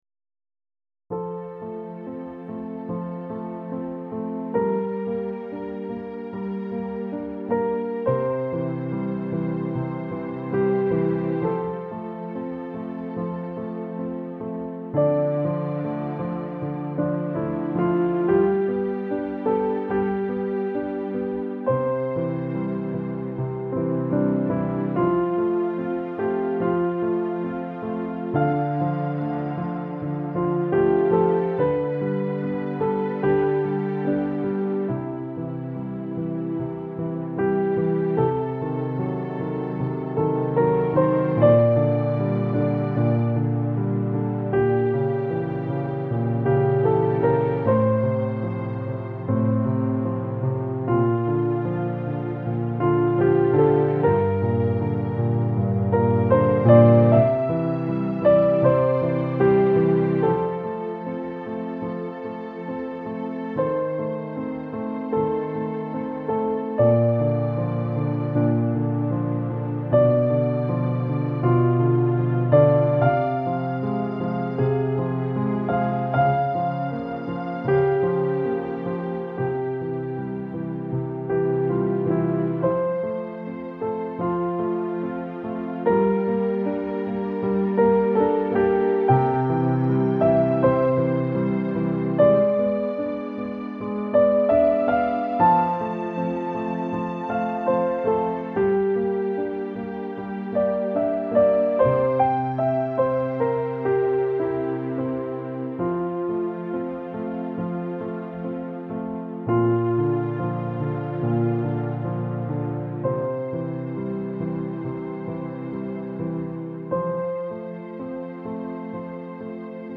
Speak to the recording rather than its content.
To turn off the background music, click once or twice on the arrow on the audio bar at the bottom of the page …